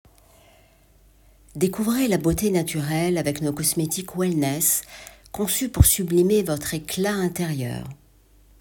49 - 60 ans - Mezzo-soprano